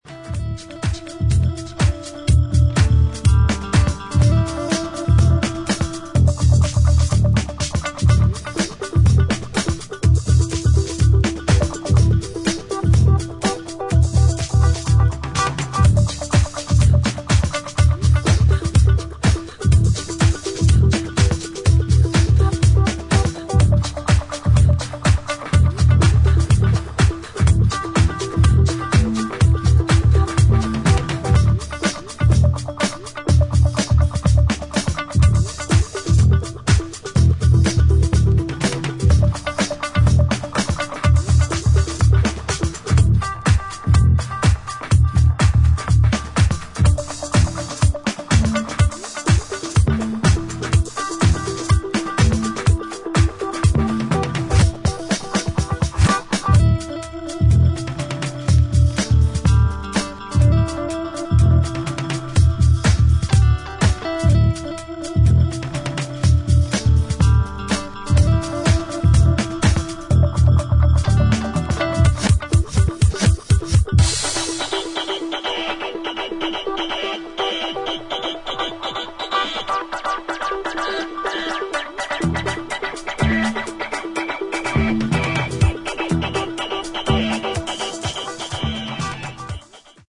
抜けの良いタイトな4つ打ちにダビーな生楽器の音色が絡む、ディープでファンキーなウェストコースト・スタイルハウス。